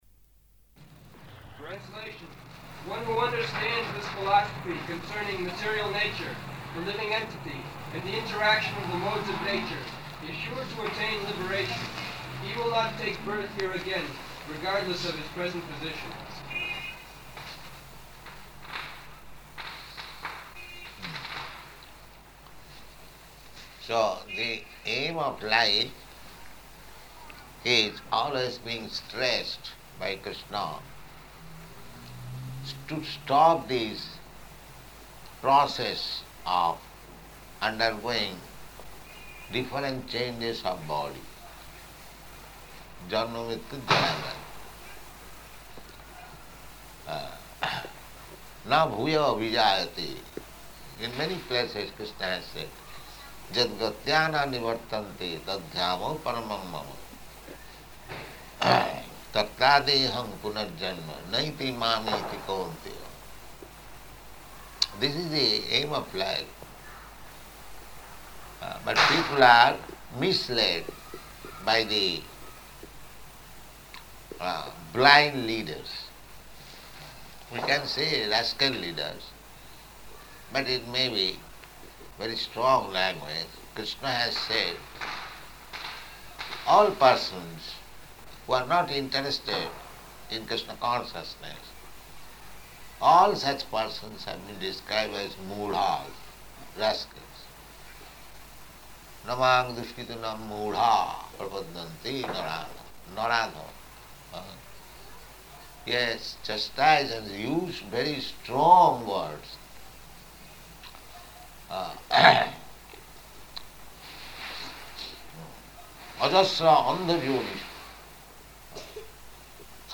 Location: Bombay
[loud sound of firecrackers throughout] Na bhūyo 'bhijāyate.